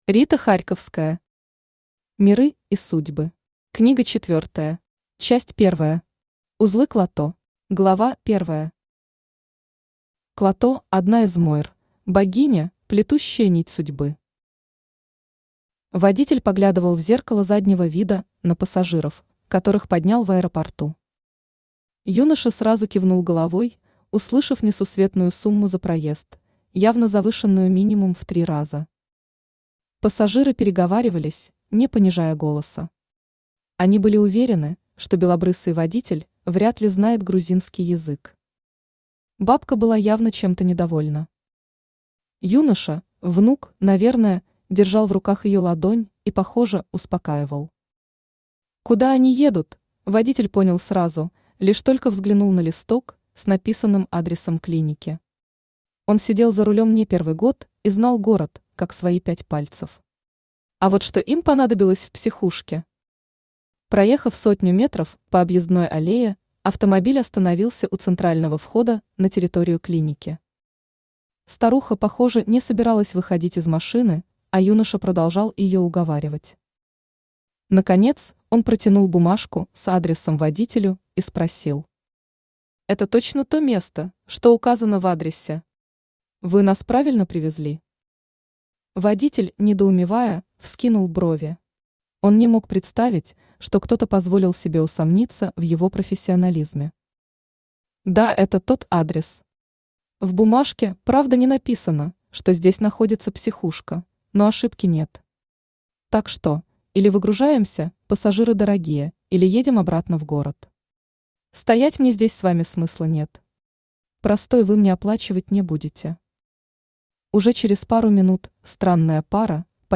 Аудиокнига Миры и судьбы. Книга четвёртая | Библиотека аудиокниг
Книга четвёртая Автор Рита Харьковская Читает аудиокнигу Авточтец ЛитРес.